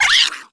Index of /App/sound/monster/misterious_diseased_dog
damage_2.wav